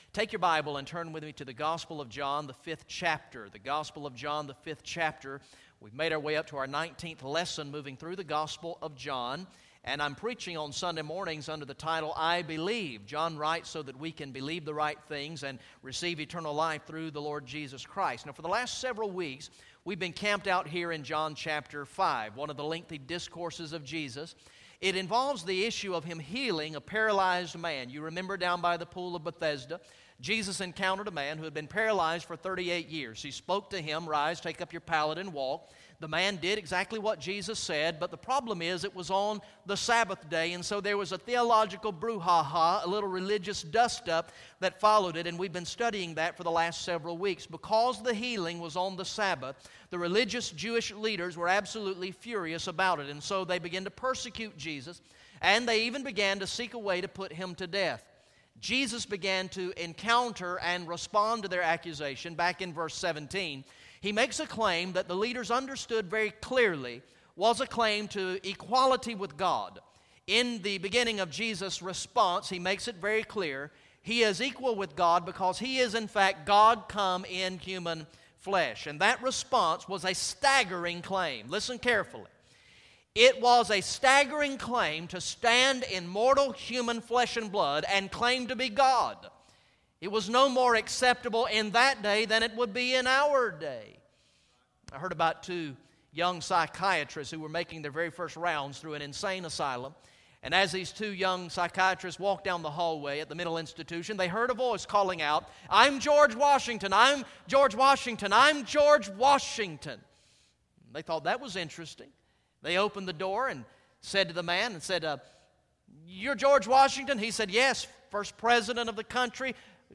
Message #19 from the sermon series through the gospel of John entitled "I Believe" Recorded in the morning worship service on Sunday, August 17, 2014